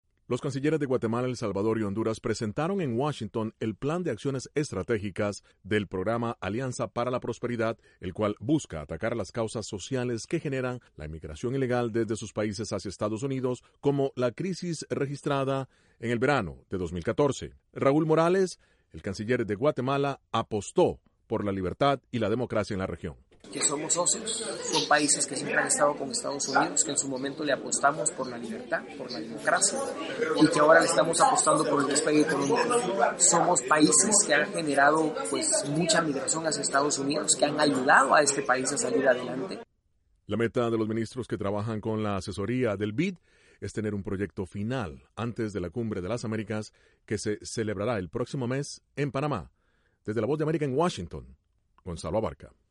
INTRO: El canciller de Guatemala, Raúl Morales, busca que un proyecto conjunto contra la migración ilegal hacia Estados Unidos esté listo antes de la Cumbre de las Américas. Desde la Voz de América, en Washington